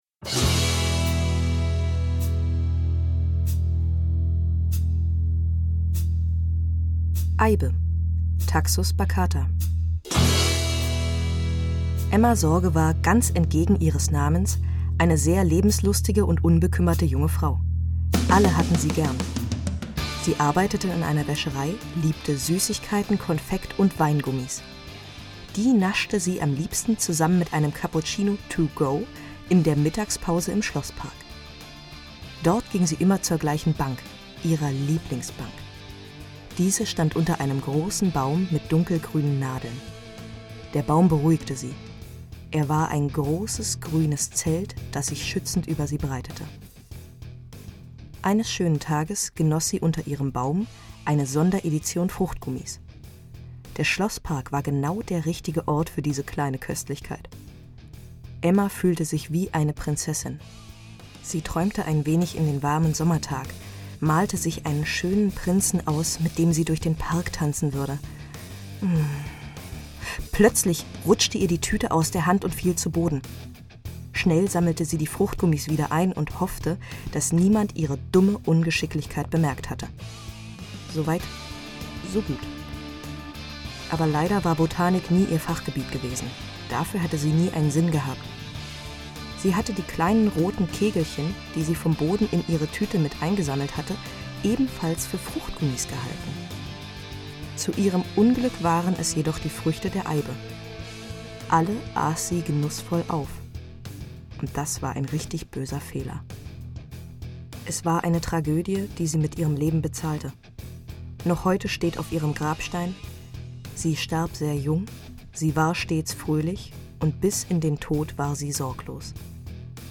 jederzeit auf eigene Faust: HÖRSPAZIERGANG HORTUS TOXICUS